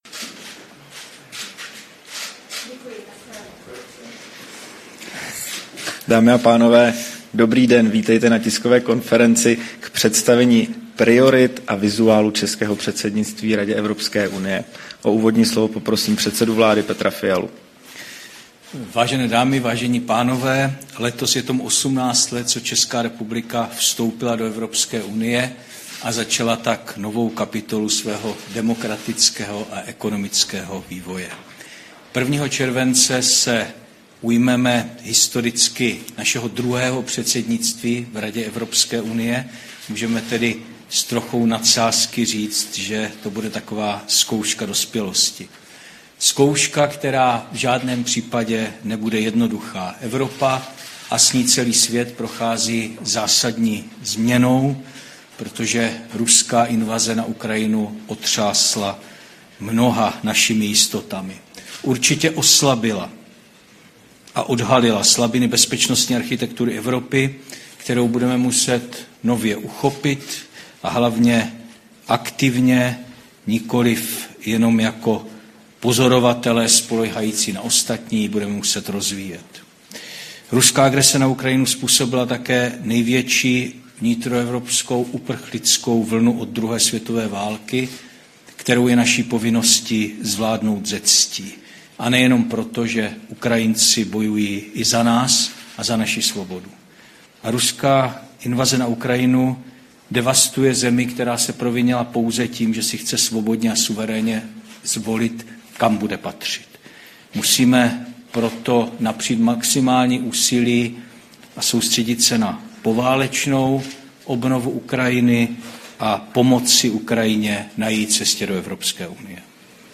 Tisková konference po jednání vlády k představení priorit a loga českého předsednictví EU, 15. června 2022